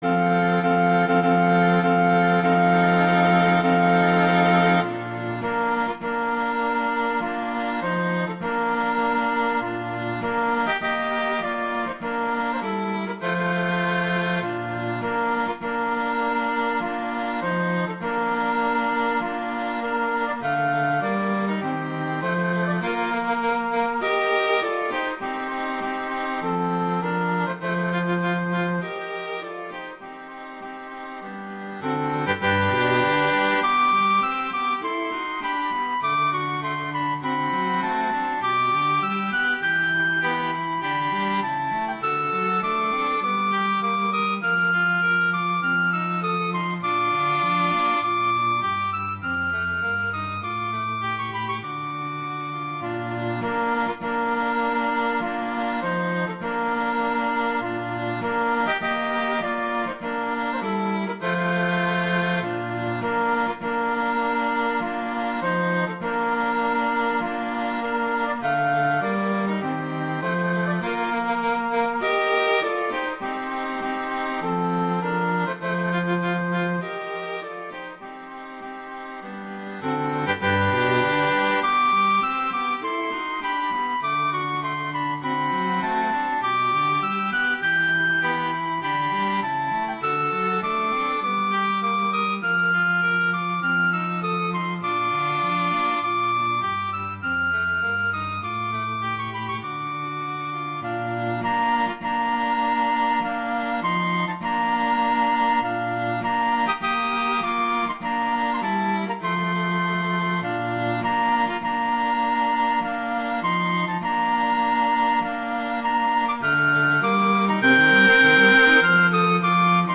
Instrumentation: flute, violin & cello
arrangements for flute, violin and cello
wedding, traditional, classical, festival, love, french